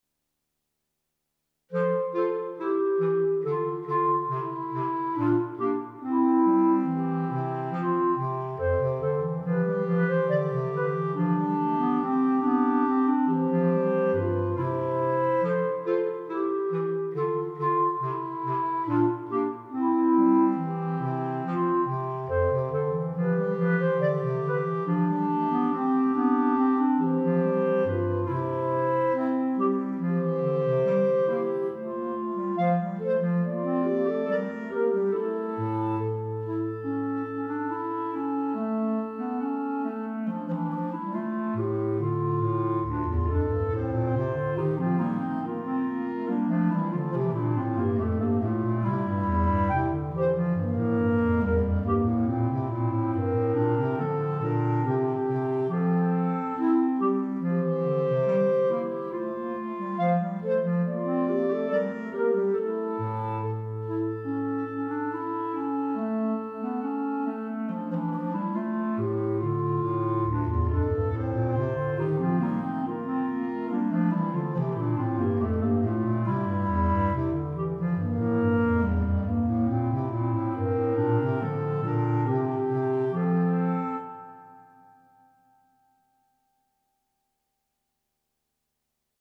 Right click to download Gavotte minus Clarinet 1